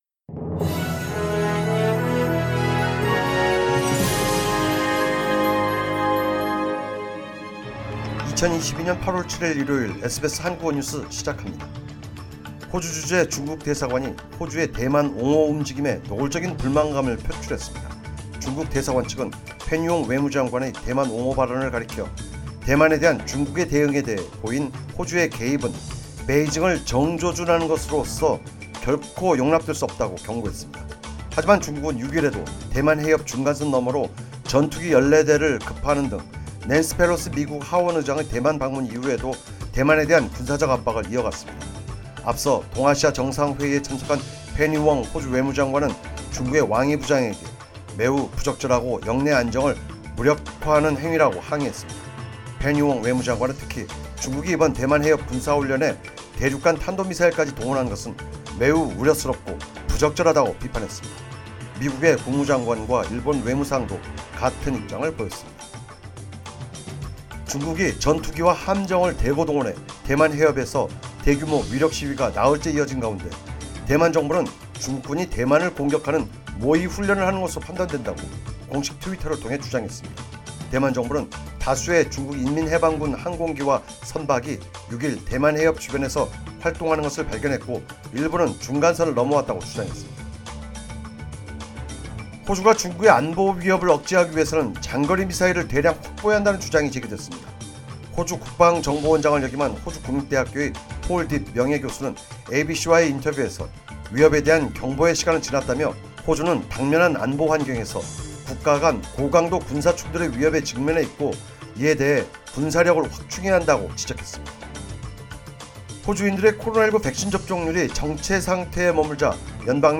2022년 8월 7일 일요일 SBS 한국어 뉴스입니다.